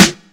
Snares
GUnit SD4.wav